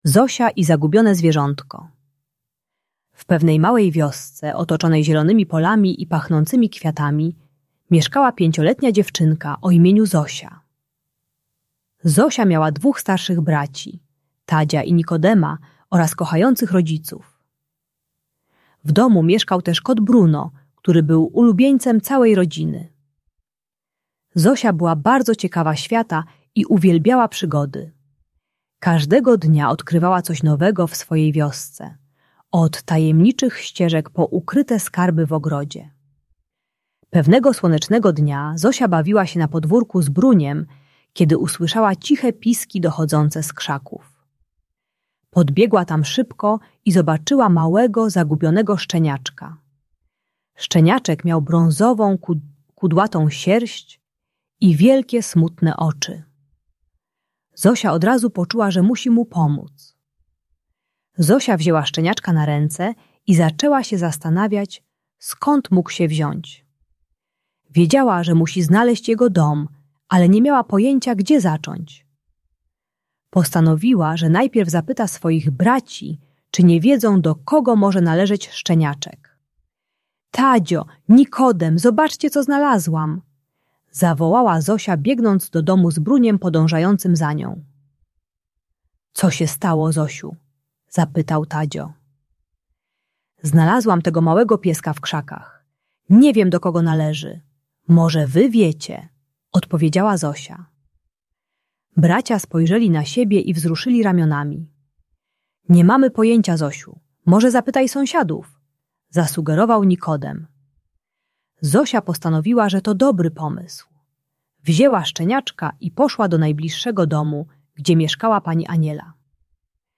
Ta audiobajka o mówieniu prawdy uczy, dlaczego szczerość jest ważna i jak przyznać się do kłamstwa. Pomaga dziecku zrozumieć, że rodzice nie będą się złościć, gdy powie prawdę.